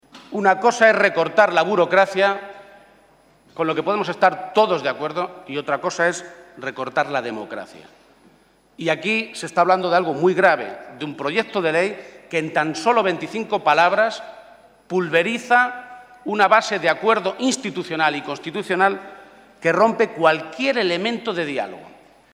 Emiliano García-Page durante su intervención en el Senado
Audio Page-primera intervención Senado 4